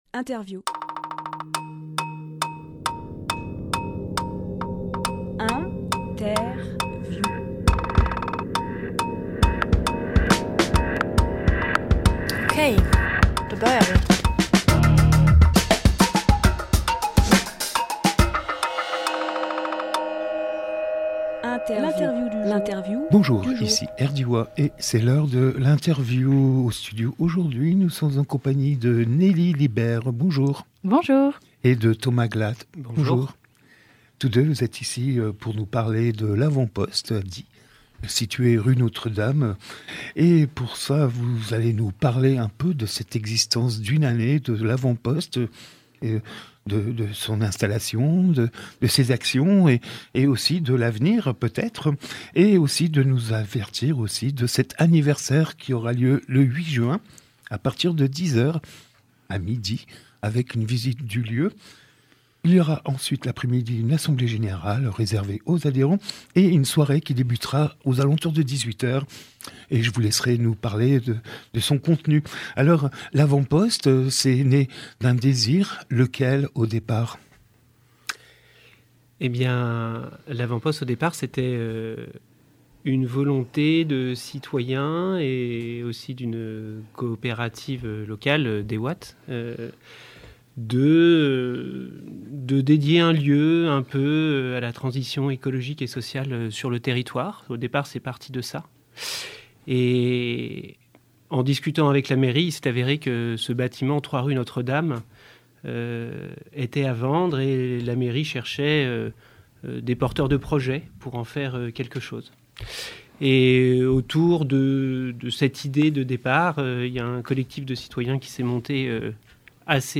Emission - Interview L’Avant-Poste fête un an d’activités Publié le 4 juin 2024 Partager sur…
Lieu : Studio RDWA